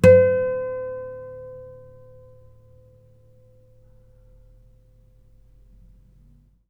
strings_harmonics / 2_harmonic
harmonic-01.wav